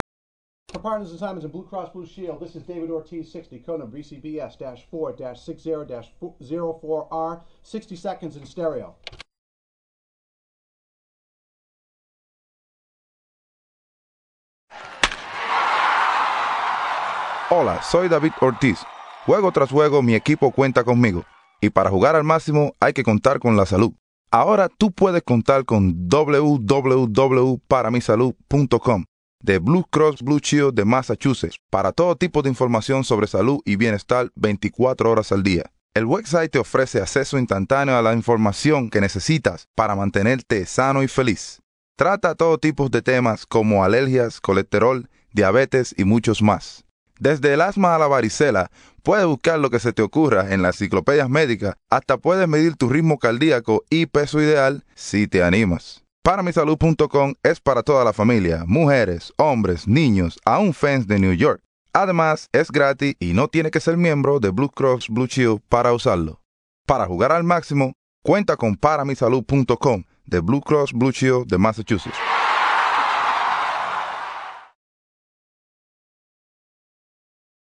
¡Para mi Salud! radio spot featuring David “Big Papi” Ortiz